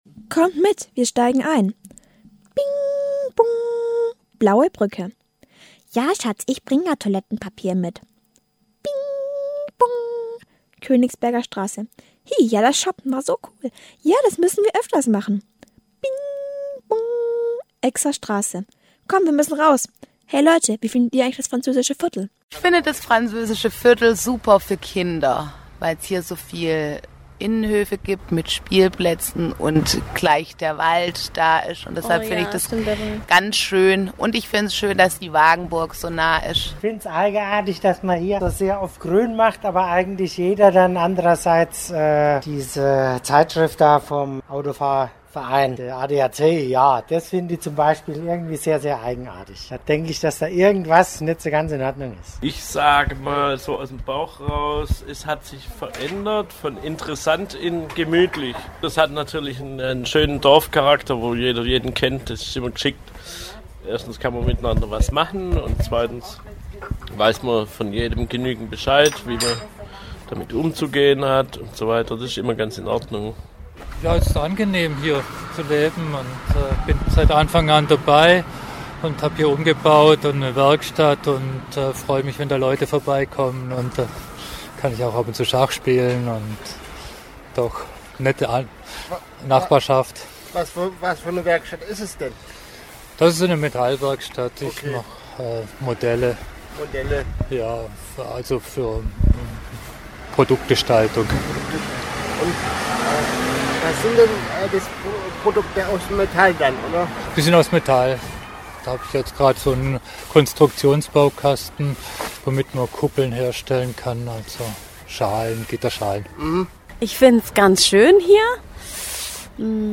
Eine Umfrage im Französischem Viertel zum Französischen Viertel hat die inklusive Redaktion von Total Normal durchgeführt.
47117_Umfrage_Franz-Viertel_fertig.mp3